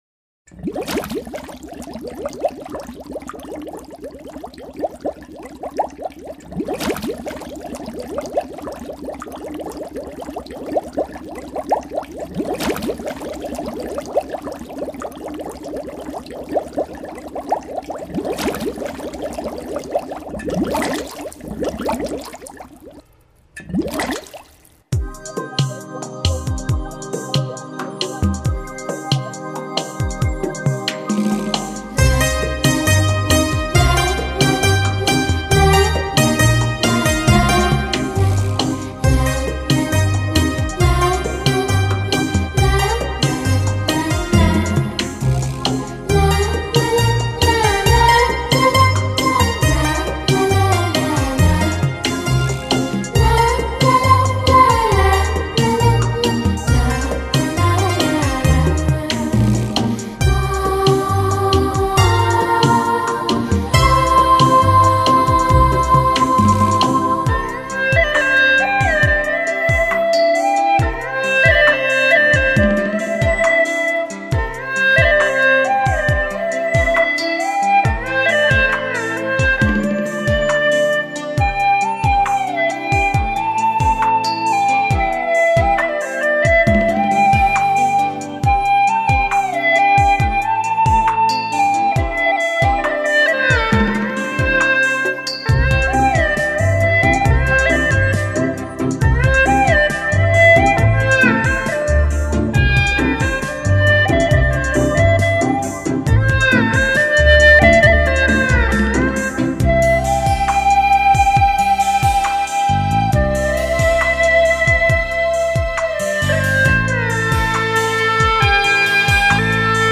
那是葫芦丝的声音，它华丽而又清冷，在裂帛一样的悠扬中潜伏着淡淡 的伤感。